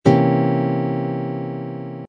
Stability Dissonant, unstable, unresolved
C Dominant seventh flat ninth chord voicing #2
c-dominant-seventh-flat-ninth-chord-voicing-2.mp3